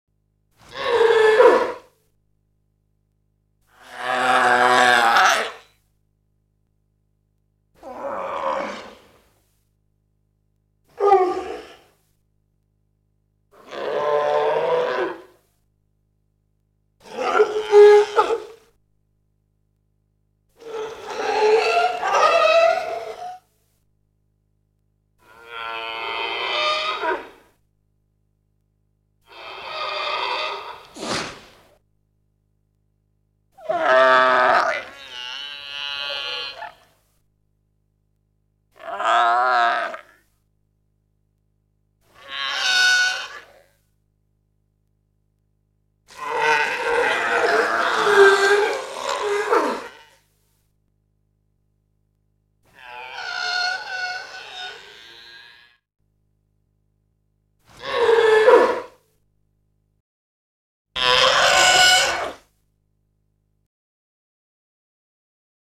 دانلود صدای حیوان آبی 6 از ساعد نیوز با لینک مستقیم و کیفیت بالا
جلوه های صوتی